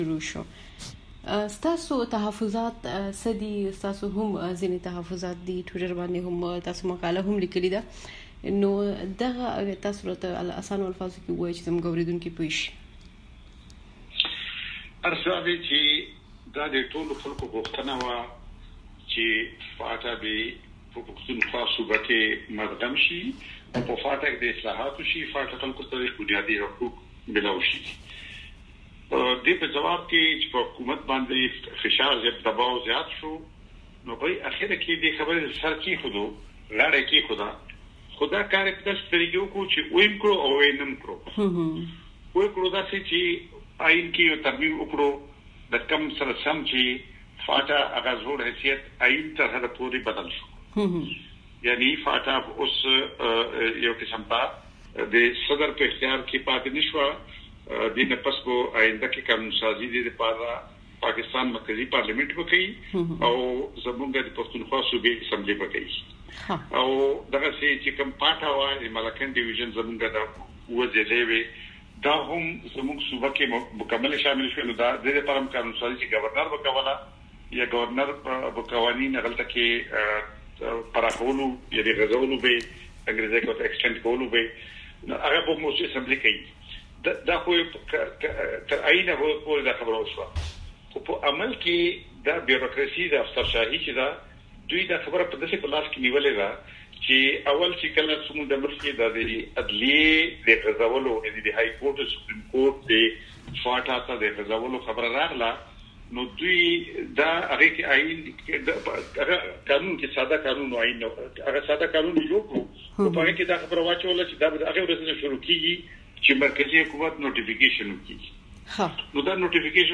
د افراسیاب خټک سره مرکه